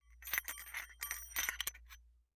Metal_46.wav